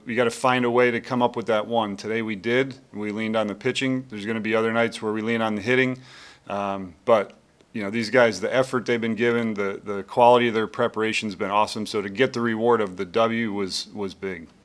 Royals mgr. Matt Quatraro on being prepared to play in the tight games